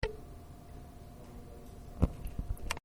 Arts and Letters Commission November 2025 Meeting Audio 1.mp3